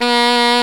Index of /90_sSampleCDs/Roland L-CDX-03 Disk 1/SAX_Alto Short/SAX_Pop Alto
SAX A 2 S.wav